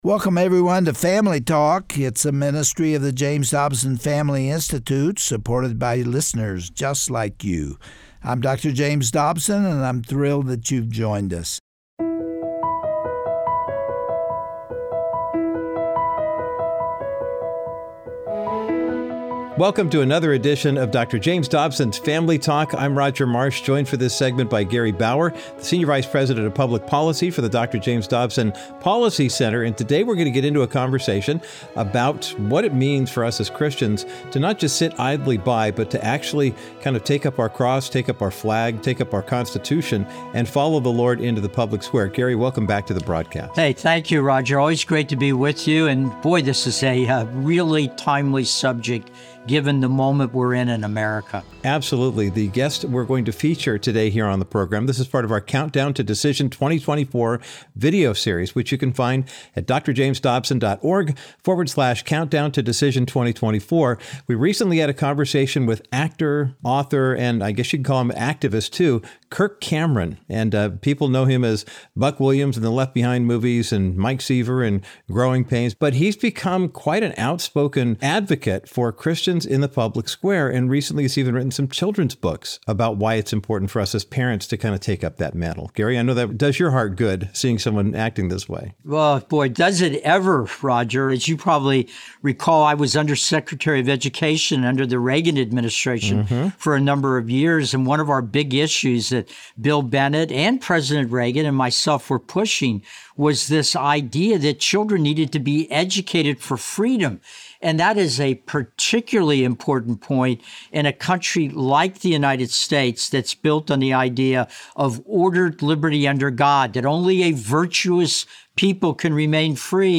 Parenting has never been for the faint at heart, but in our society today, moms and dads face a whole new set of hurdles that can seem insurmountable. On today’s edition of Family Talk, we’ll hear from Kirk Cameron, who is an evangelist, speaker, and actor, about specific challenges parents are experiencing across our country.